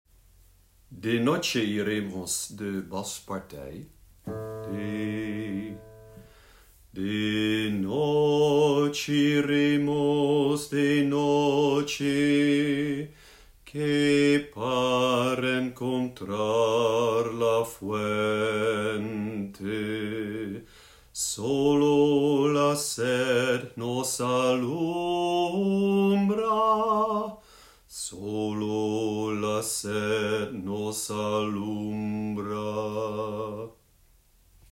De-noche-iremos-bas.mp3